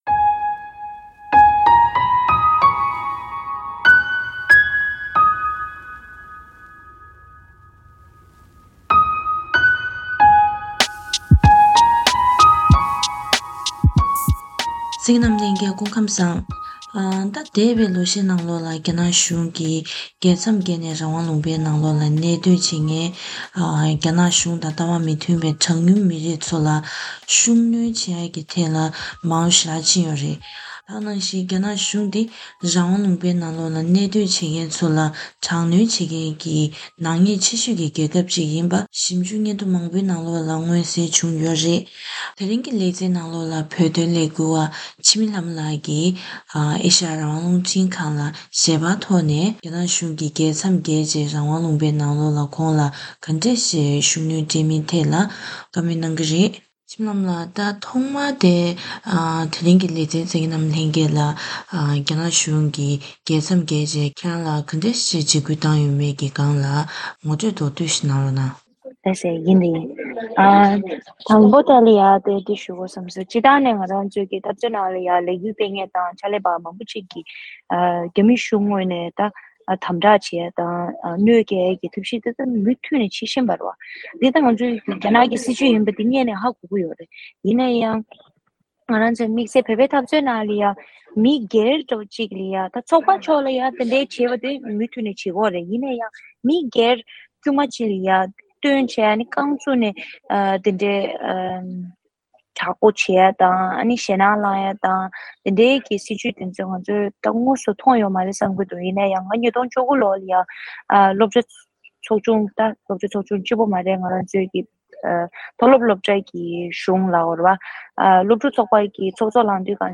ཞལ་པར་བརྒྱུད་ནས་བཀའ་དྲི་ཞུས་པ་ཞིག་གསན་རོགས་གནང་།